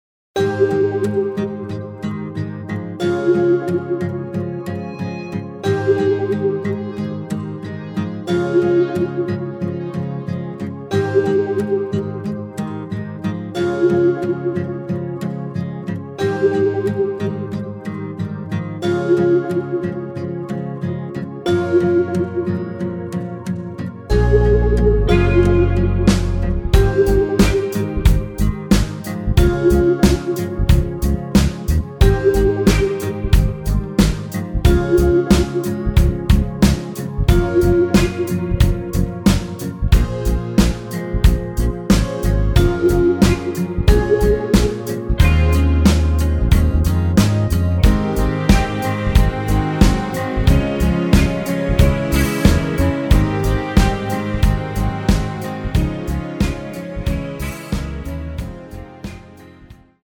Ab
앞부분30초, 뒷부분30초씩 편집해서 올려 드리고 있습니다.
중간에 음이 끈어지고 다시 나오는 이유는